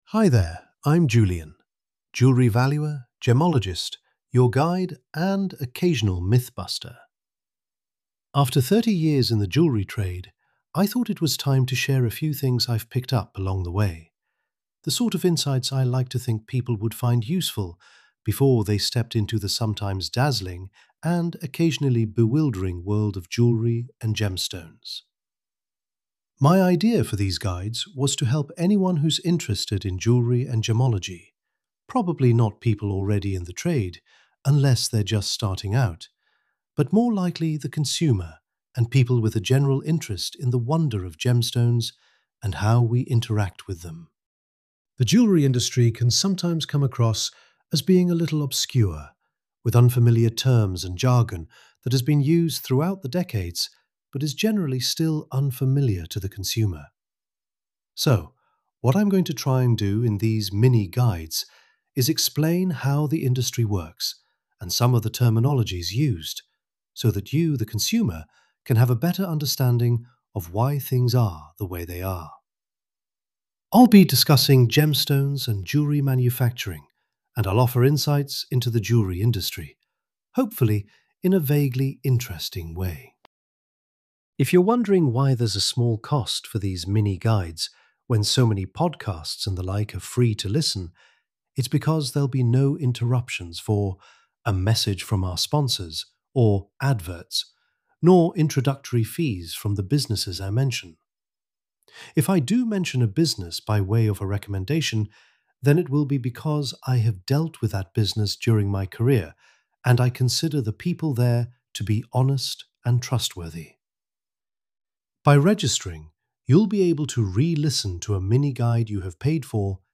Audio guides